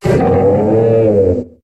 Cri d'Aflamanoir dans Pokémon HOME.